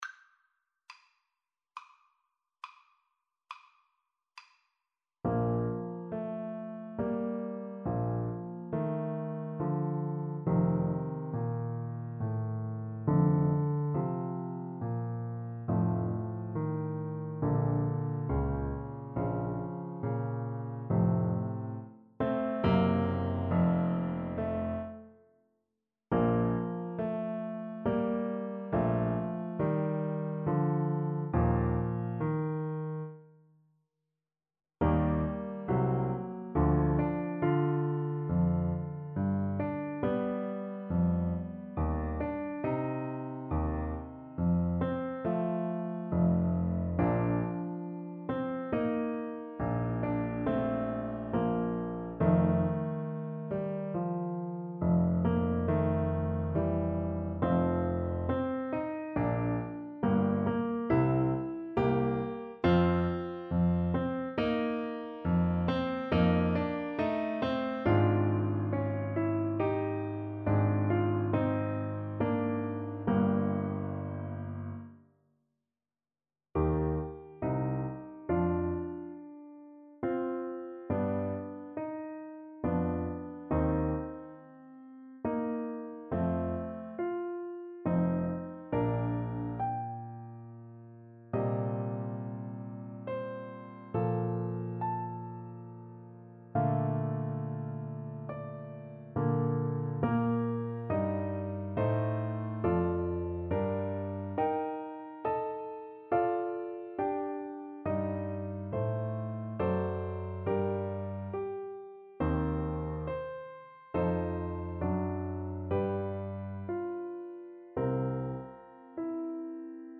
6/4 (View more 6/4 Music)
Andante =c.84 =69